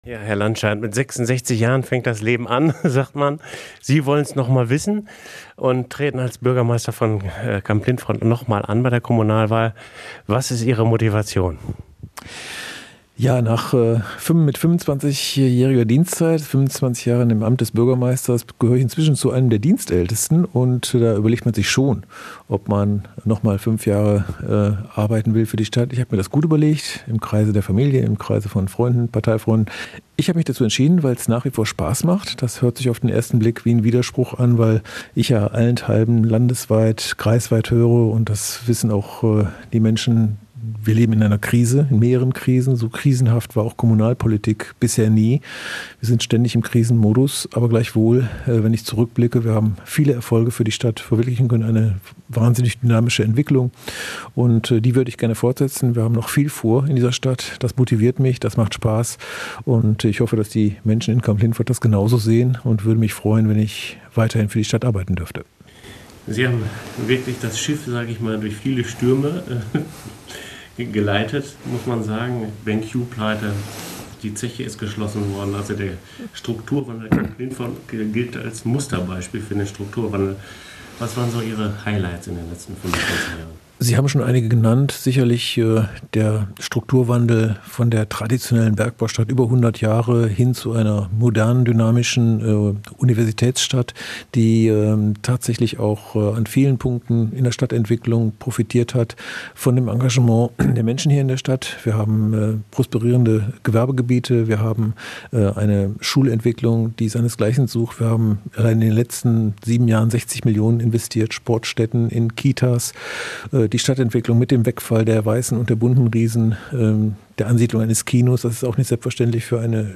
int-christoph-landscheidt.mp3